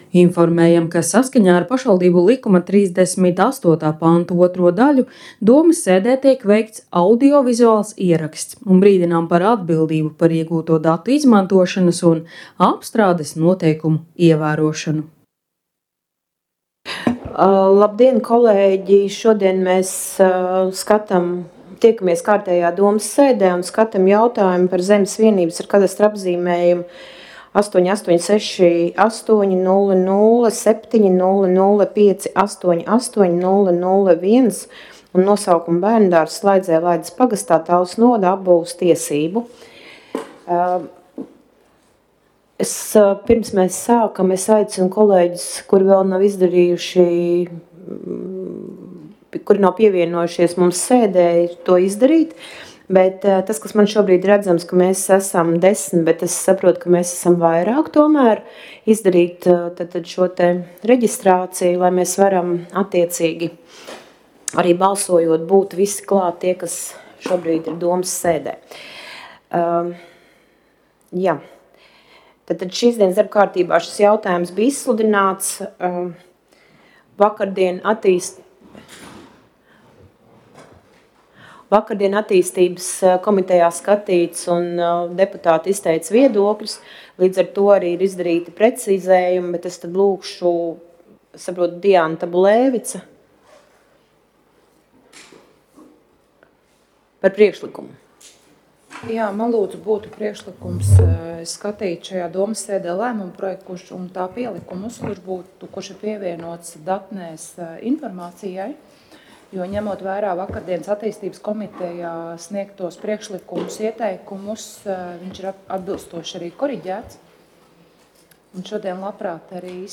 Talsu novada domes sēde Nr. 32